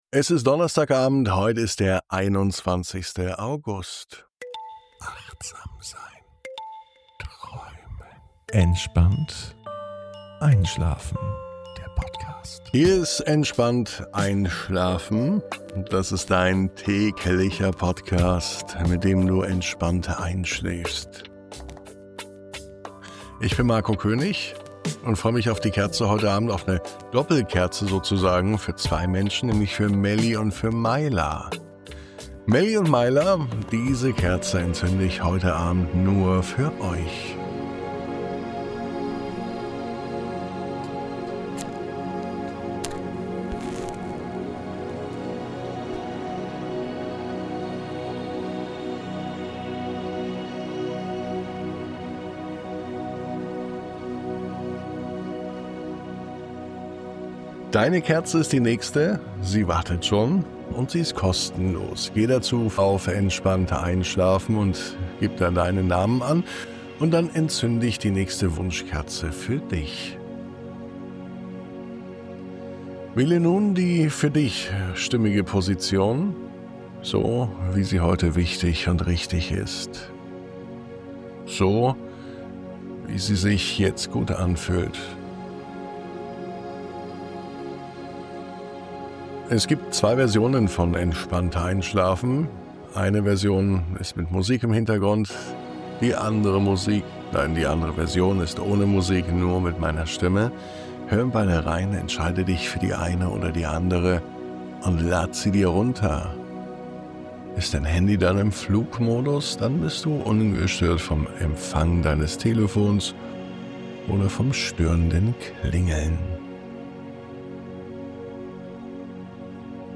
Diese Nacht begleiten dich die Bewegungen dieser stillen Pflanzen – langsam, gleichmäßig, voller Vertrauen. Du wanderst durch ein warmes Feld, spürst die Erde unter dir und wirst eins mit dem natürlichen Takt der Gelassenheit. Diese Traumreise schenkt dir Zeit – zum Durchatmen, zum Loslassen, zum Stillwerden.